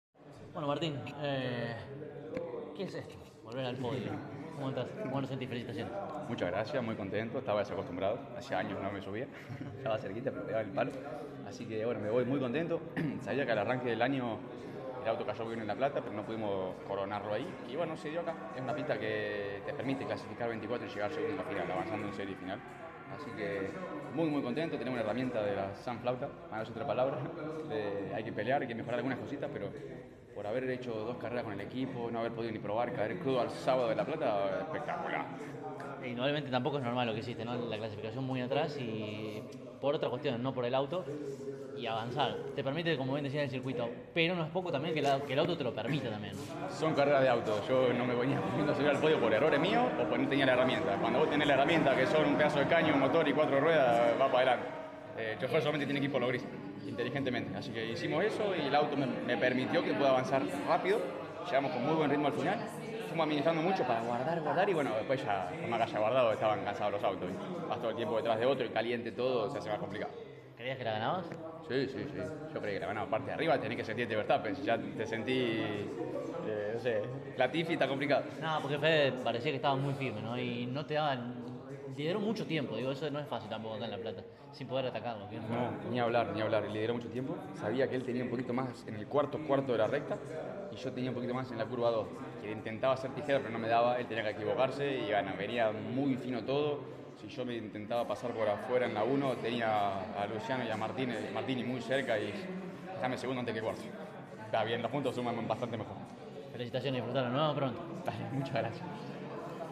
Por lo tanto, a continuación solo escucharás los testimonios de los dos primeros.